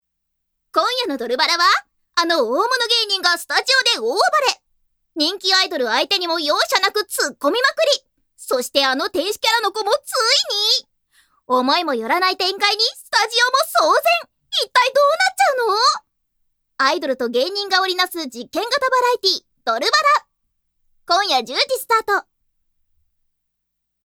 ◆幼児向けCM◆
◆ハウスCMナレーション◆
◆アプリCMナレーション◆
◆朗読ナレーション◆
◆テレビ番宣ナレーション◆